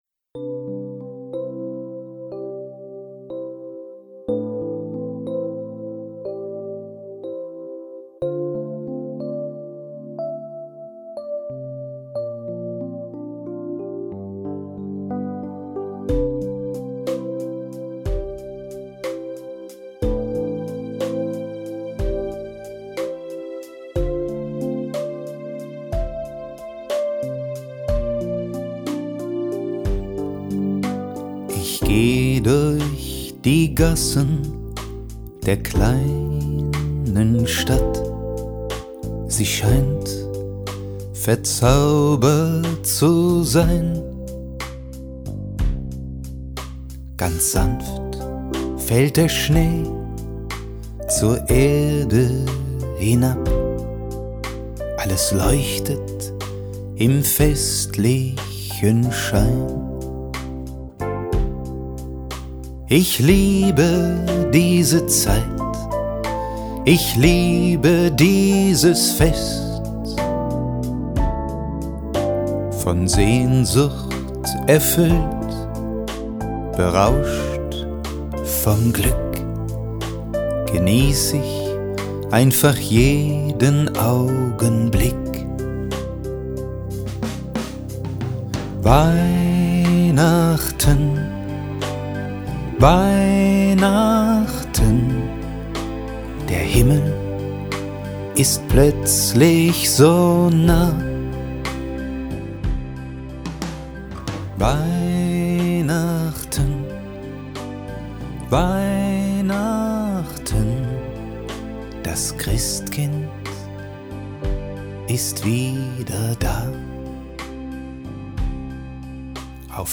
Sie können sich das Interview inklusive Songs hier anhören. Ab Minute 5:13 beginnt das Interview.